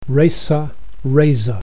racerrazor.mp3